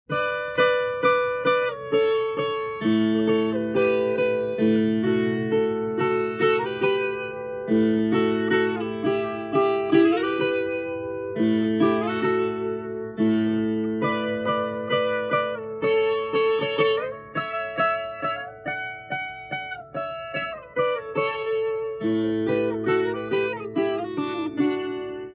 Ponteado de viola
Atividade musical de caráter lúdico que consiste na execução de melodias dedilhadas na viola.
ponteadodeviola.mp3